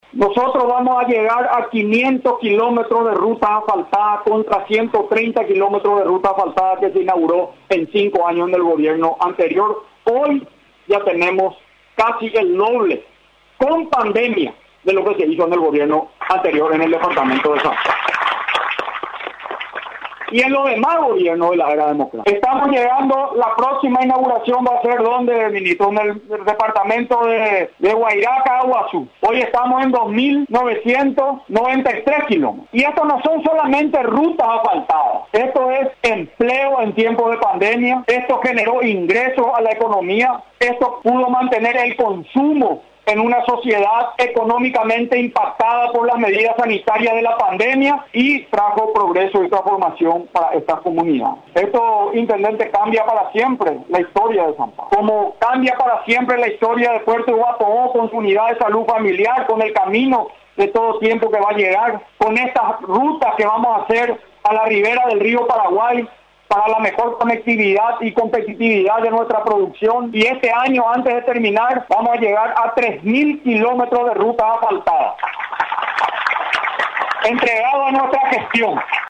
El presidente de la República, Mario Abdo Benítez, destacó este martes durante el acto de inauguración de la pavimentación asfáltica del tramo Cruce Liberación – Choré – San Pablo – Ruta 11- que el año se cierra con el desafío de avanzar hacia un tiempo de esperanza, unión y de recuperación económica.